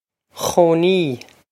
chónaí khoh-nee
Pronunciation for how to say
This is an approximate phonetic pronunciation of the phrase.